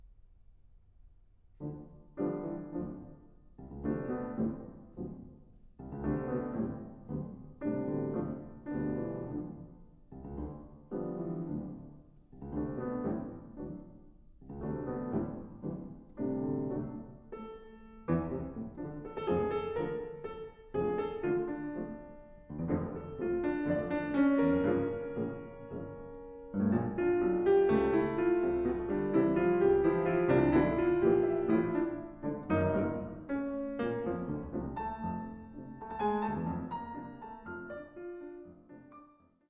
arrangement for 2 pianos